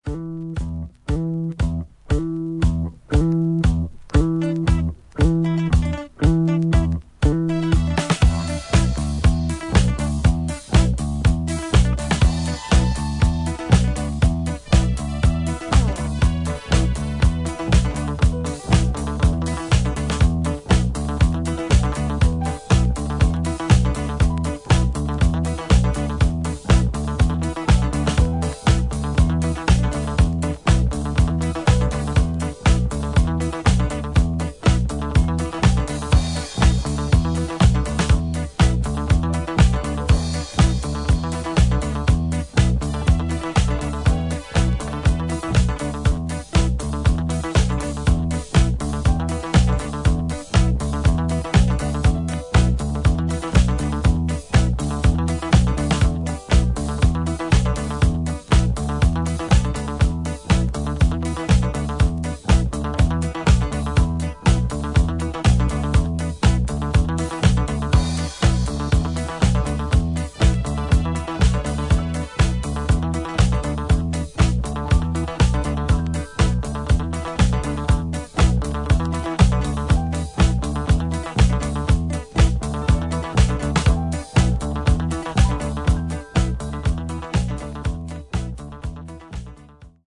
計3トラックをフロア仕様にエディットしたDJユース盤